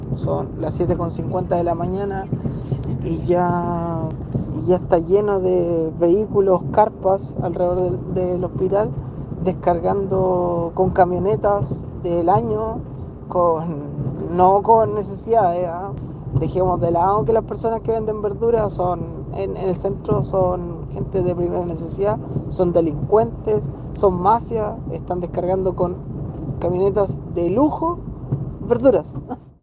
Un auditor de Radio Bío Bío comentó que obstaculizan los dos accesos peatonales al hospital, venden todo tipo de productos y todo comienza muy temprano en el día.